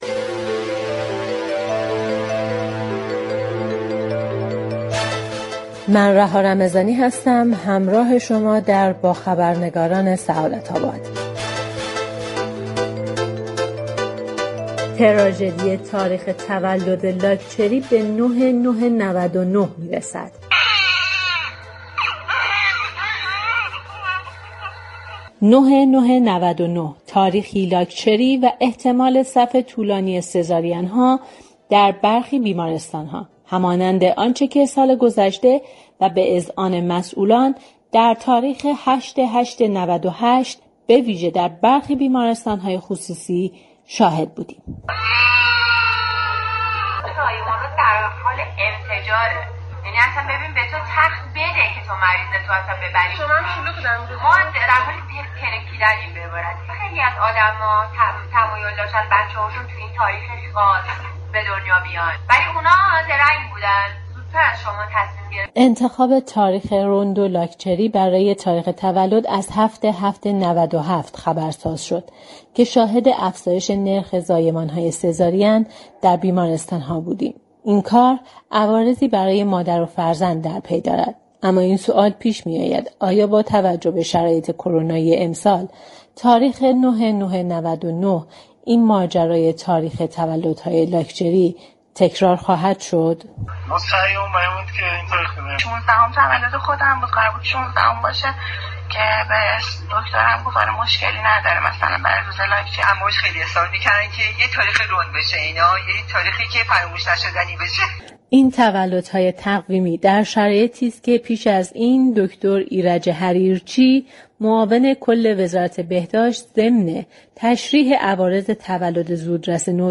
محسنی بندپی عضو كمیسیون بهداشت و درمان مجلس شورای اسلامی گفت: متاسفانه آمار تعداد زایمان های سزارین در روزهای لاكچری به سه برابر رسیده است اگر وزارت بهداشت نتواند كاری كند ما باید در مجلس قوانین سختگیرانه تری را تصویب كنیم.